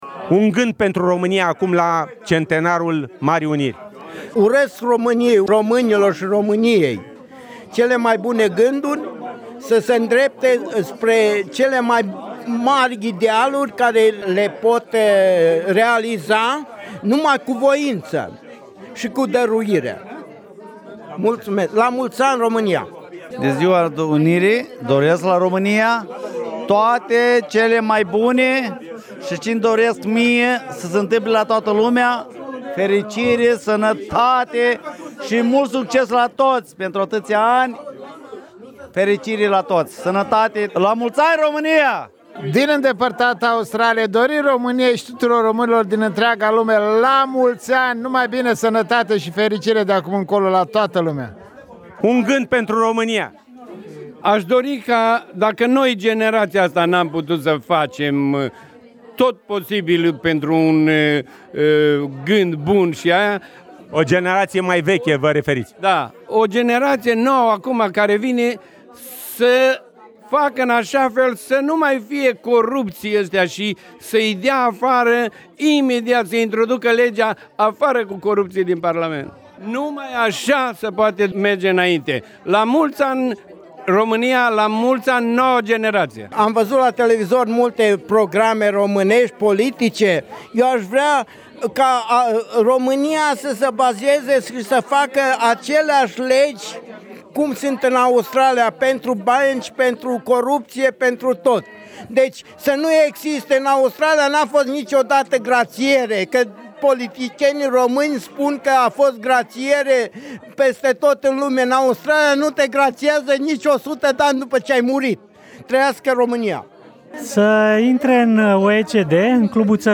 Vox pop la Centrul Cultural Roman din Melbourne, pe tema Zilei Nationale si a Centenarului Marii Uniri.